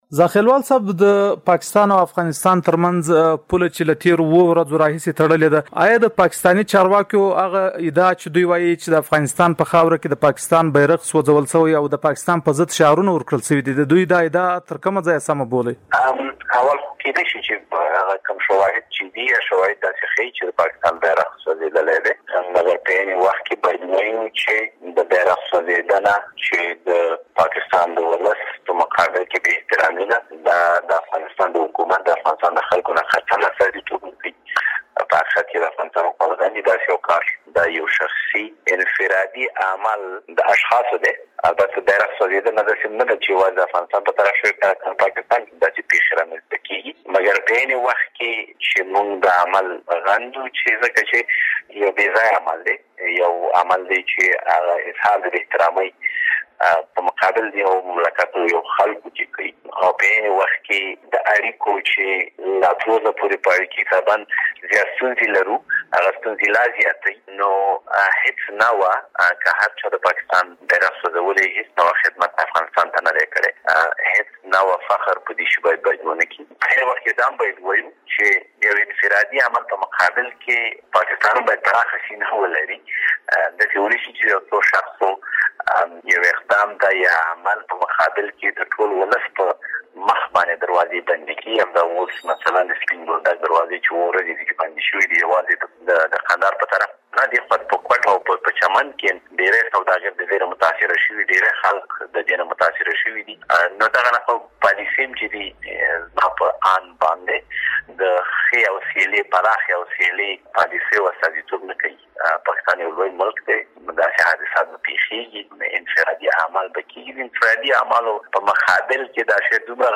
له زاخېلوال سره مرکه